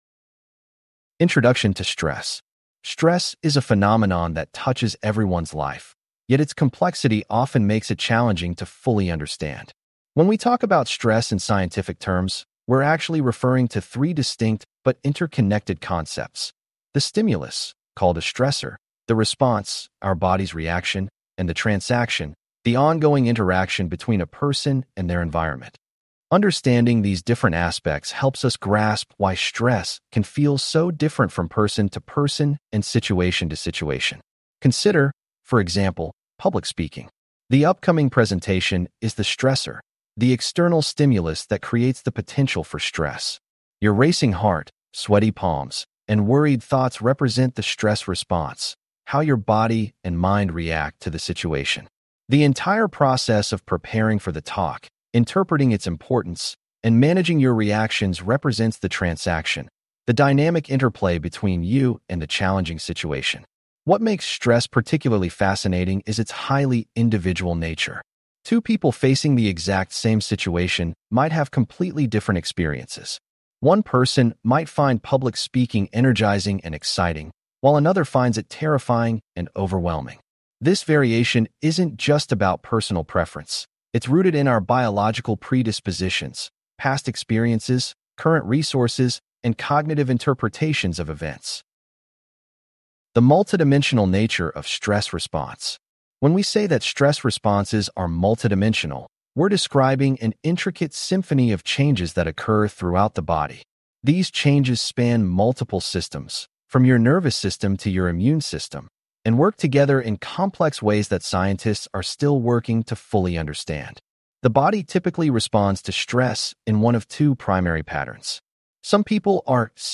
This unit covers Stressors and Stress, The Stress Response is Multidimensional, Biopsychosocial Model, Allostatic Load Model, Stress-Diathesis Model, System-Wide Effects of Stress, Stressful Life Events, Psychological Factors in Stress, Acute and Chronic Stress Responses, Psychoneuroimmunology, Cognitive Appraisal of Stressors and Coping, Personality Dimensions, and Resources to Buffer Stress. Please click on the podcast icon below to hear a full-length lecture.